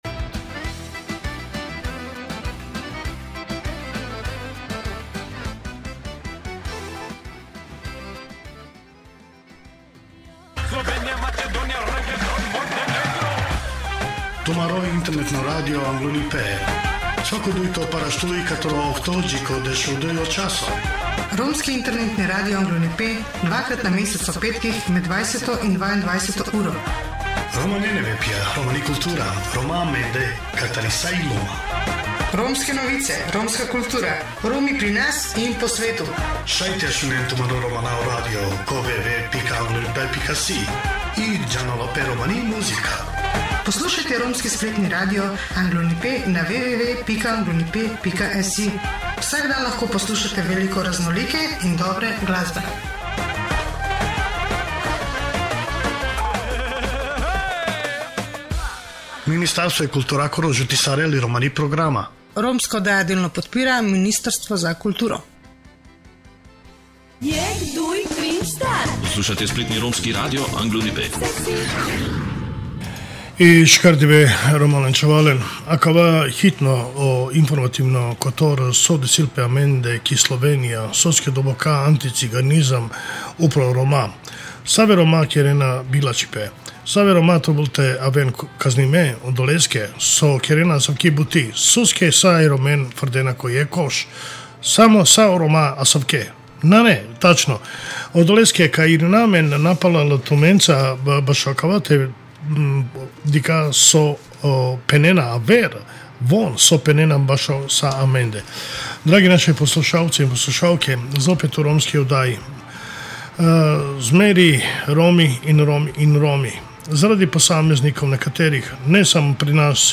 RADIJSKA ODDAJA, četrtek 07. avgust 2025 ob 18.00 Spoštovani poslušalci, dragi prijatelji oddaje "Romano Anglunipe", in vsi, ki verjamete v moč spomina ter opomina!
Osrednji del oddaje pa bo posvečen pretresljivemu in pomembnemu nagovoru g. Veljka Kajtazija, spoštovanega hrvaškega poslanca za romsko manjšino. Njegove besede, izrečene med letošnjo globoko ganljivo komemoracijo, 2. avgusta, na romskem pokopališču iz II. svetovne vojne v Ušticah, delu kompleksa Jasenovac, nas bodo popeljale v osrčje spomina.